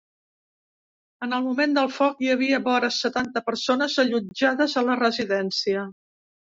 Leer más (Inglés) Noun Prep Adv Frecuencia B2 Pronunciado como (IPA) [ˈbɔ.ɾə] Etimología (Inglés) Heredado de latín ōra Cognado con español orilla In summary Inherited from Latin ōra.